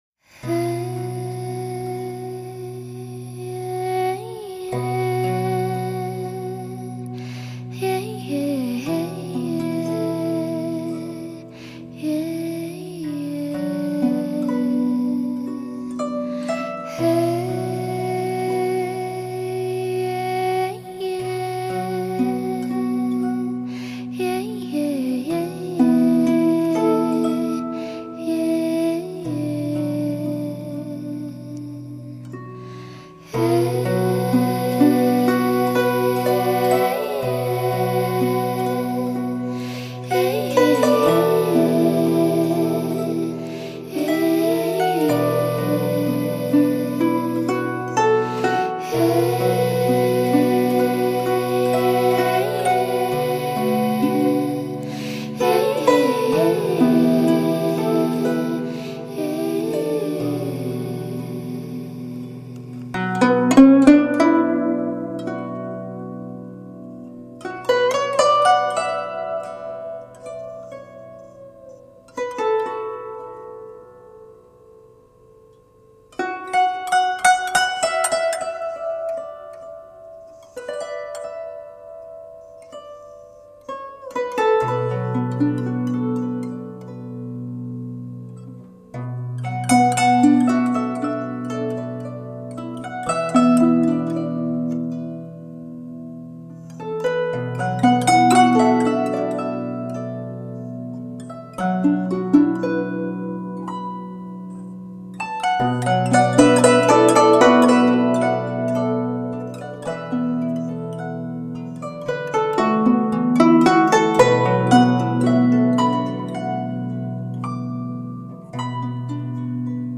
融合出非常精致、独特的中国古典新音乐。